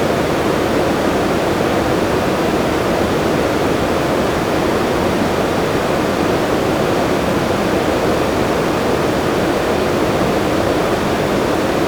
The first waveform shown below (Gaussian Waveform) is a Gaussian noise waveform with the same spectral shape as a jet aircraft at afterburner.
Both waveforms below are 16-bit mono .WAV files, sampled at 44.1 kHz.
gaussian.wav